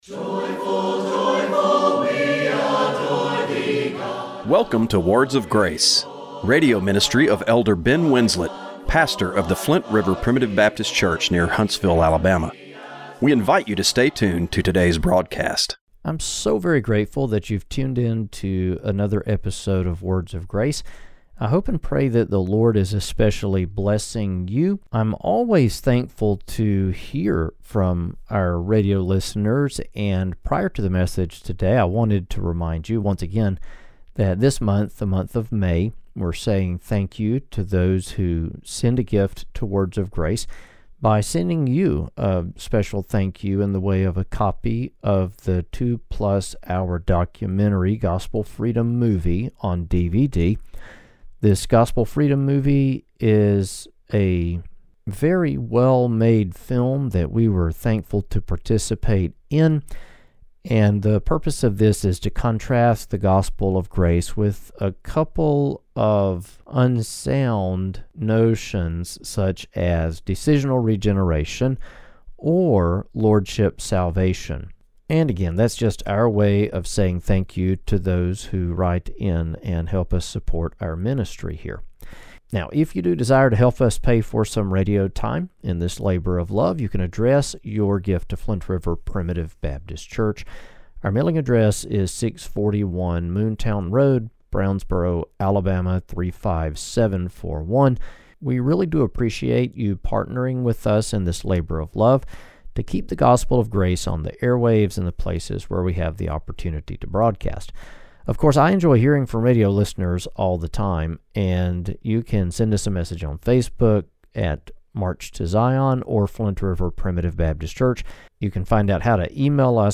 Radio broadcast for May 25, 2025.